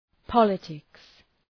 {‘pɒlıtıks}